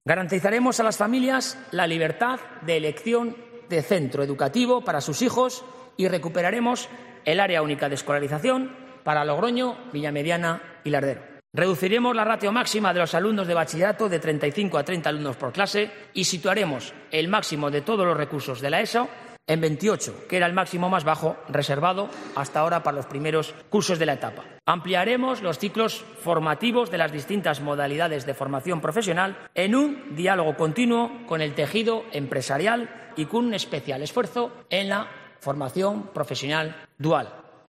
El Parlamento regional ha acogido este mediodía la primera jornada del pleno de investidura del candidato a la presidencia del Gobierno de La Rioja, Gonzalo Capellán.